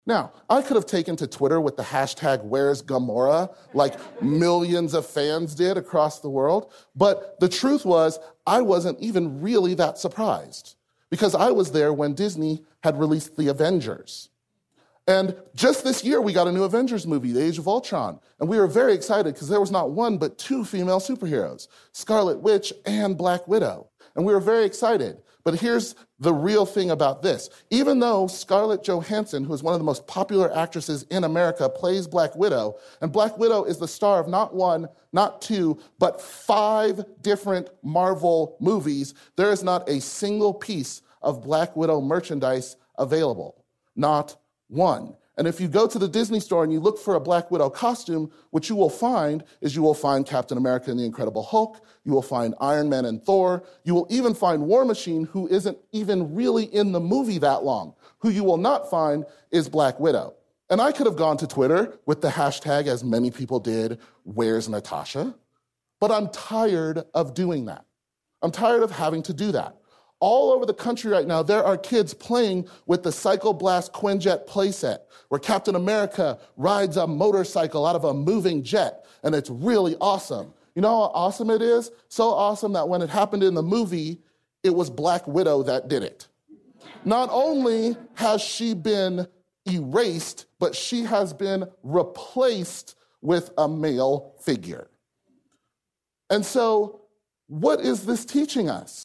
TED演讲:让我们带上女超人!(7) 听力文件下载—在线英语听力室